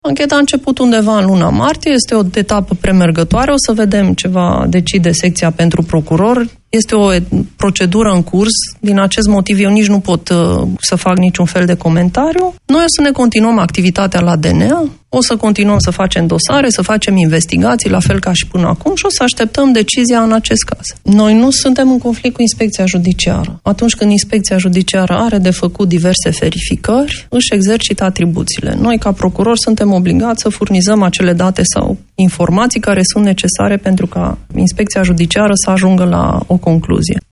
Invitată în emisiunea DRUM CU PRIORITATE, la Europa FM, șefa DNA, Laura Codruța Kovesi, a fost întrebată dacă este sau nu o tentativă de intimidare propunerea făcută de inspecția judiciară, prin care aceasta cere Consiliului Superior al Magistraturii sancționarea celor 3 procurori DNA care au deschis dosarul adoptării controversatei ordonanței 13.